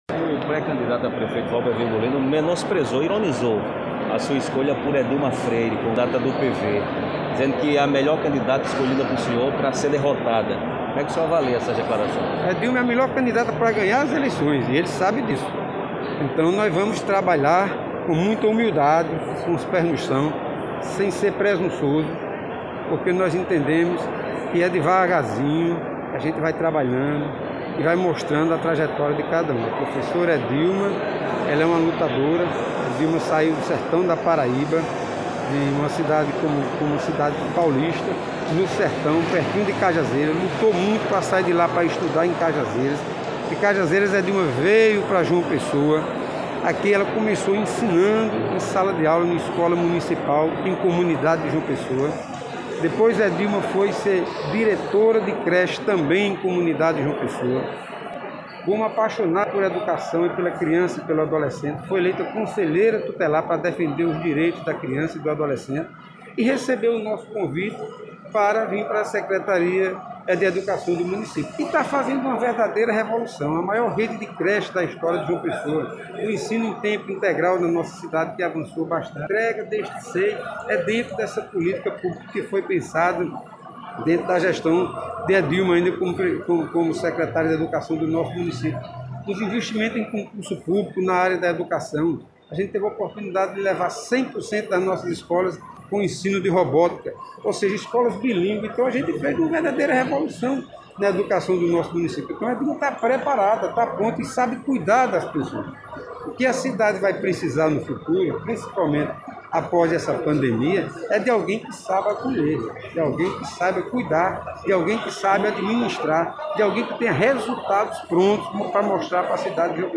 Durante entrevista nesta quarta-feira (05), o prefeito de João Pessoa, Luciano Cartaxo (PV), respondeu as críticas feitas contra Edilma Freire (PV), pré-candidata escolhida por ele para disputar a secessão na Capital, nestas eleições de 2020.